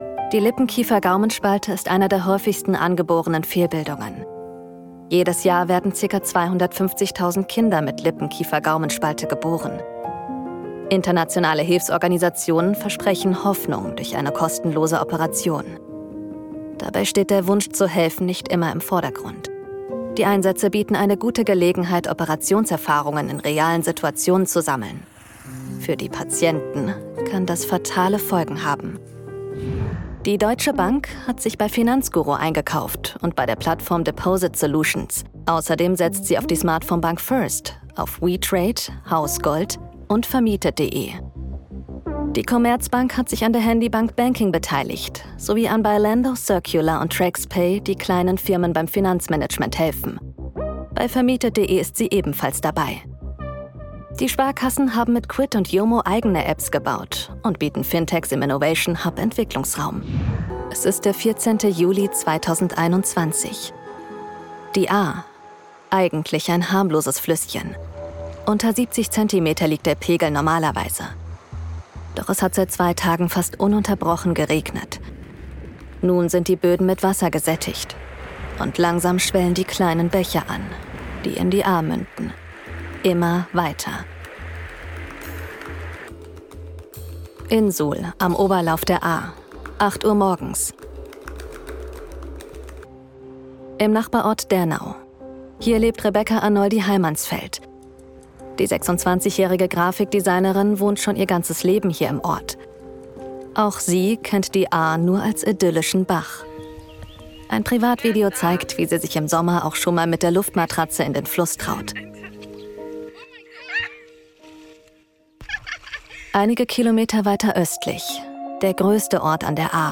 dunkel, sonor, souverän, markant, sehr variabel
Kommentar Off Dokumentation Collage
Comment (Kommentar), Doku, Off